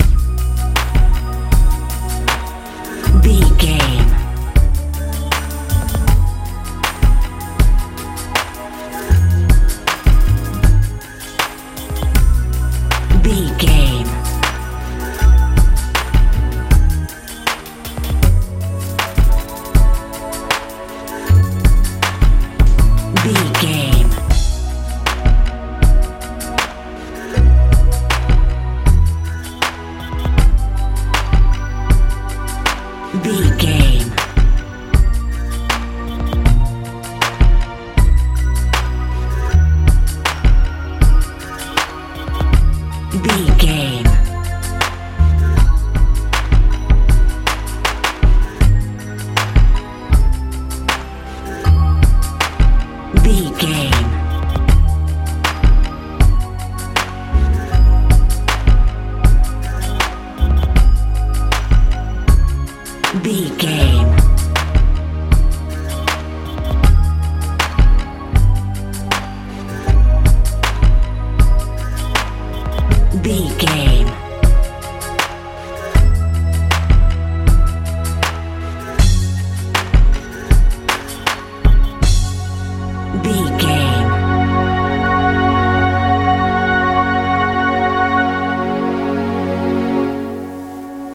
pop dance
Ionian/Major
E♭
peaceful
passionate
bass guitar
drums
synthesiser
80s
90s